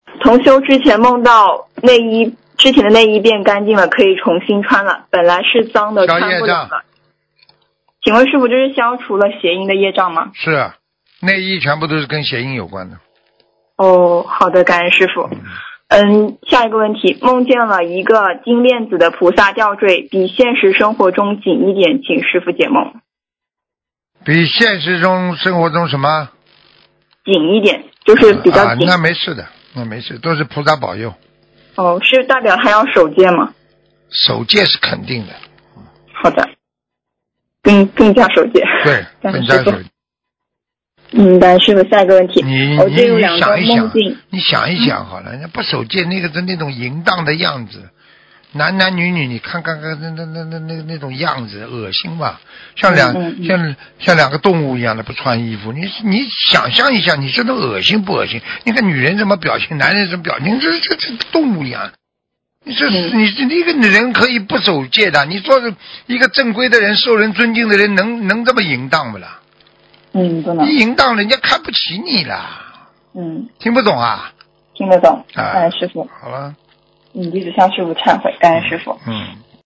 女听众同修梦到之前的内衣变干净了，可以重新穿了，本来是脏的，穿不了了。